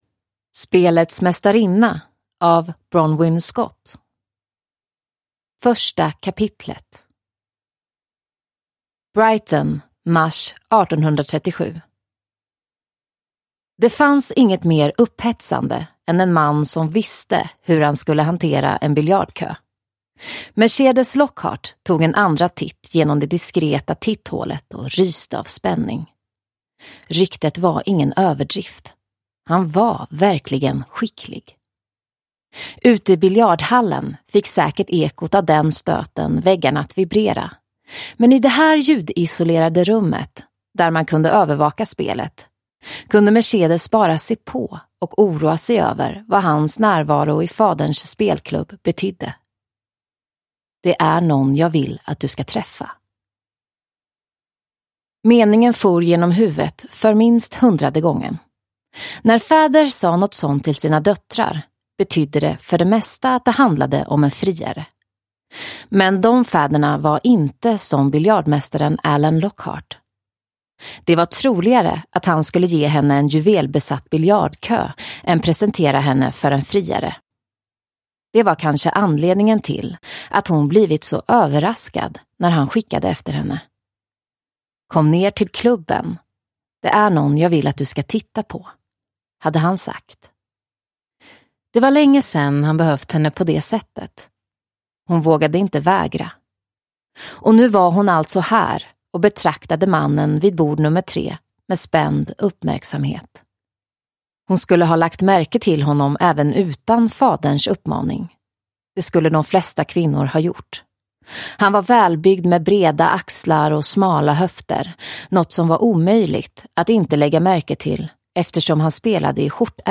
Spelets mästarinna – Ljudbok – Laddas ner